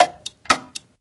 slugfest_clock_01.ogg